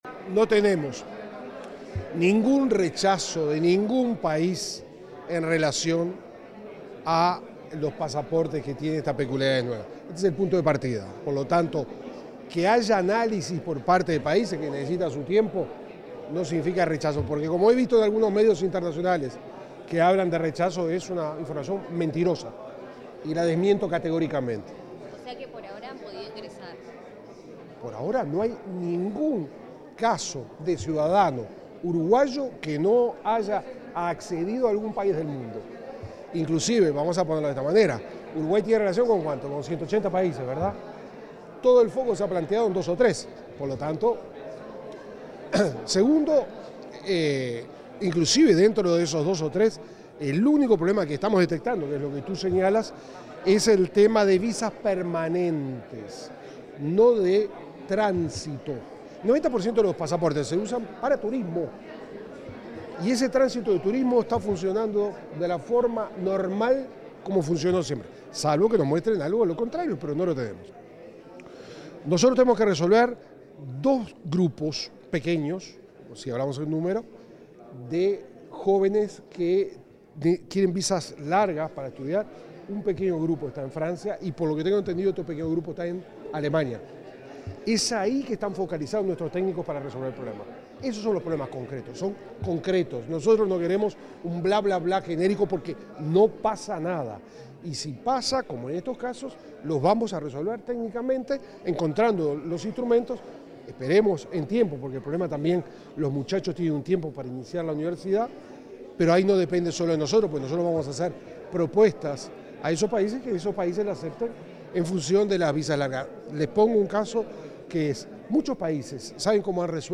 Declaraciones del ministro de Relaciones Exteriores, Mario Lubetkin
Tras un encuentro con diplomáticos acreditados en Uruguay, el canciller de la República, Mario Lubetkin, dialogó con los medios de prensa.